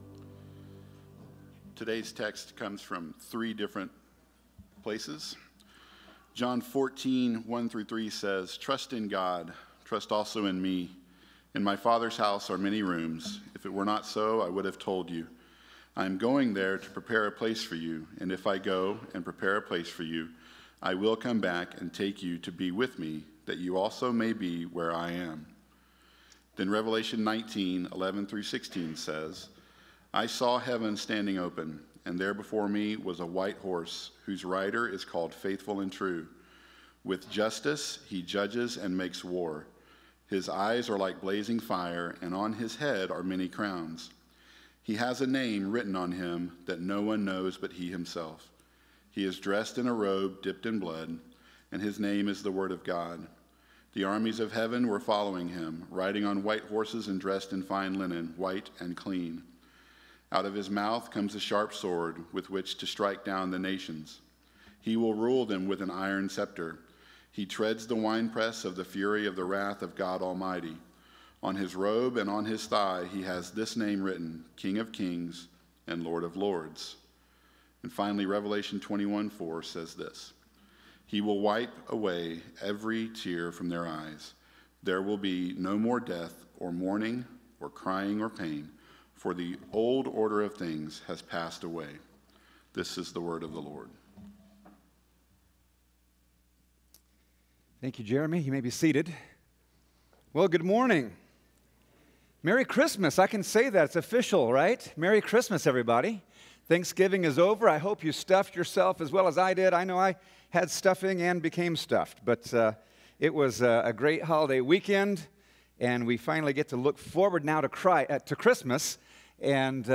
A message from the series "Standalone."